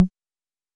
S 78_Tom3.wav